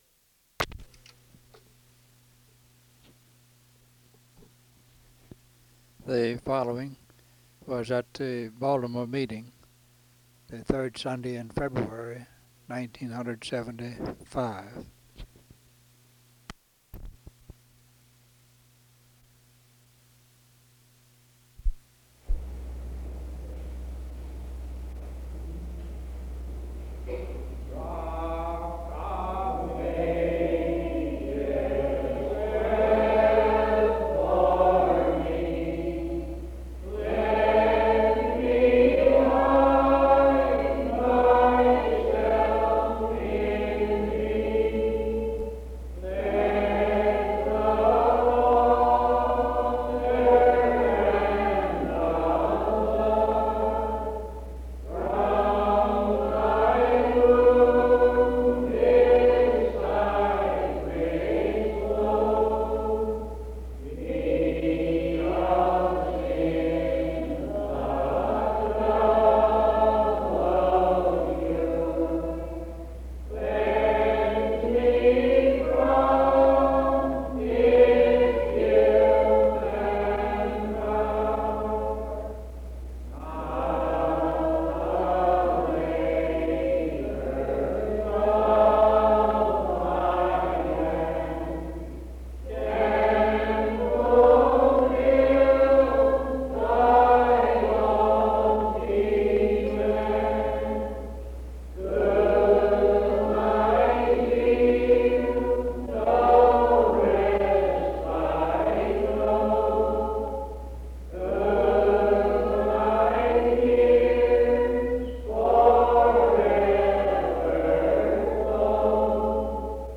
Prayer & Talks